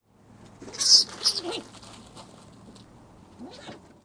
polosatij-skuns-mephites-mephites.mp3